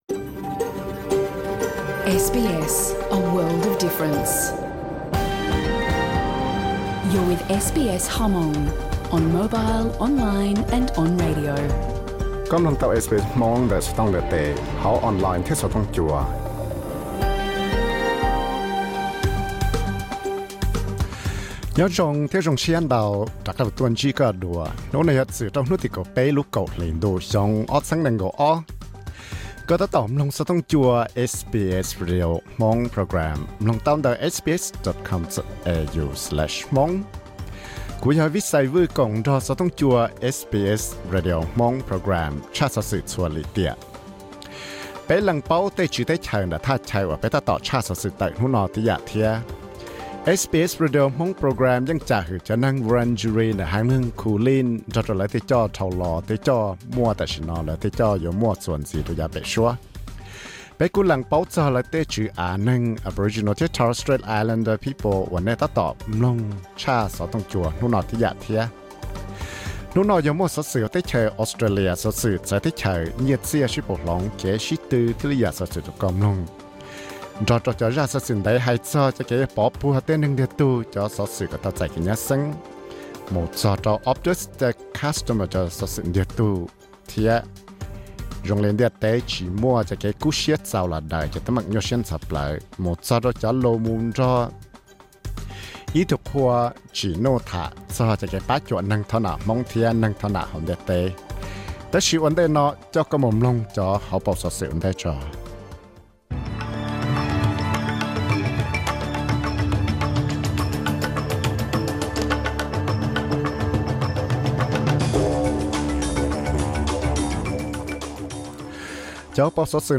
Thursday news program